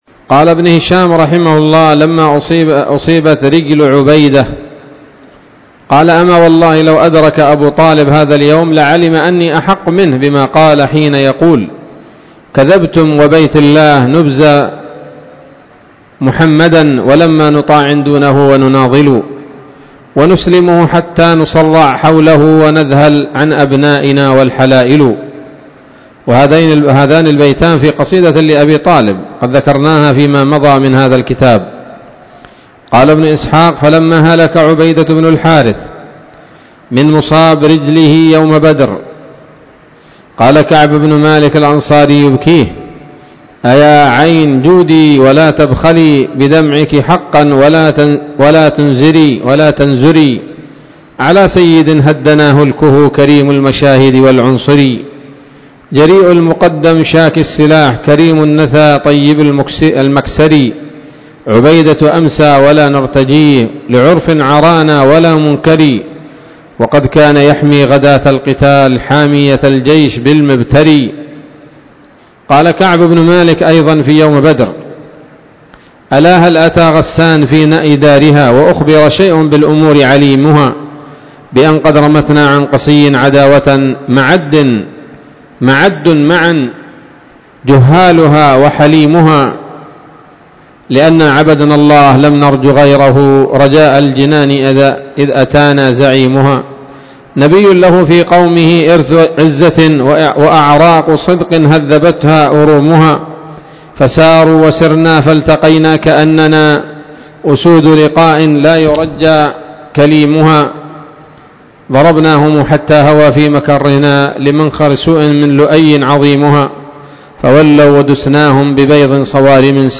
الدرس الرابع والأربعون بعد المائة من التعليق على كتاب السيرة النبوية لابن هشام